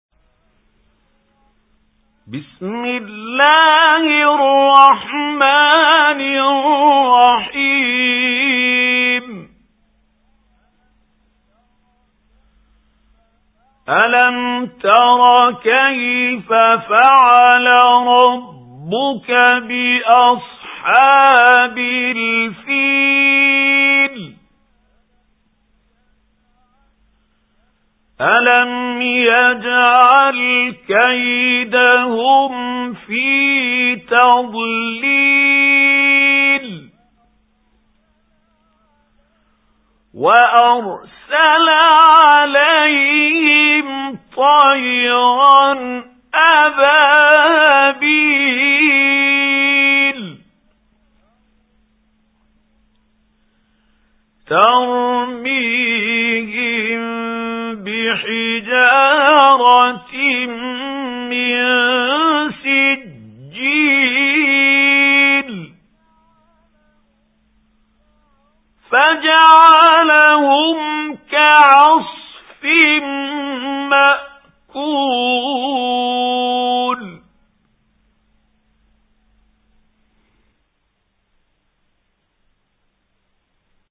سُورَةُ الفِيلِ بصوت الشيخ محمود خليل الحصري